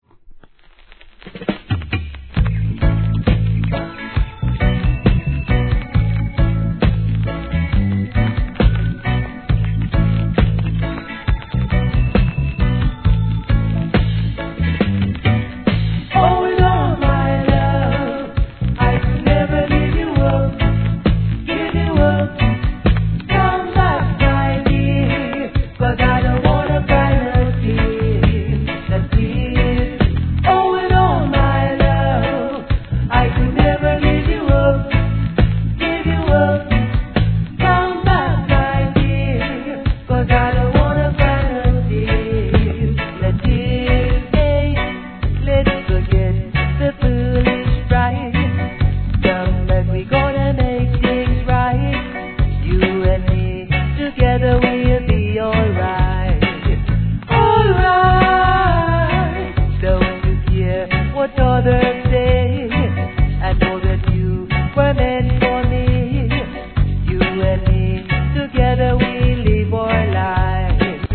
REGGAE
1981年のEASYヴォーカル＆コーラス!!